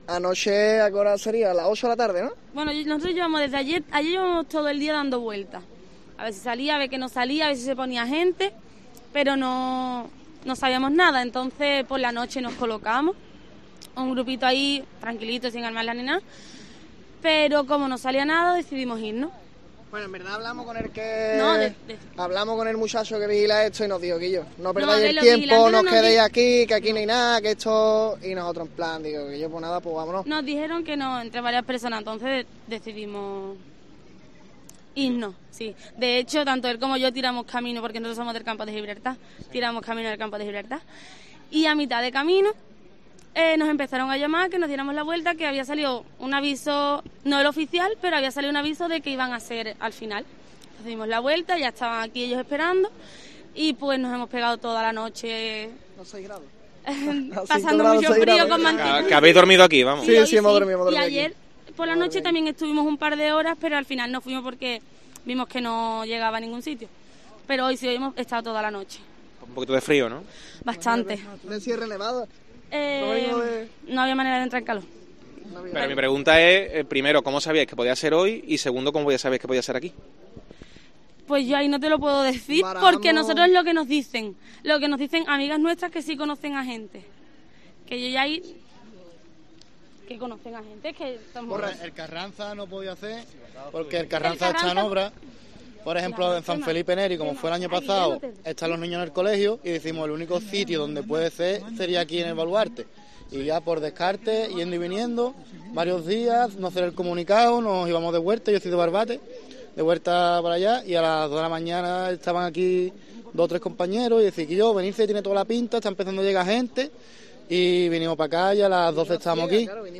AUDIO: Aficionados del Carnaval explican como han conseguido entradas para el Concurso de Agrupaciones 2019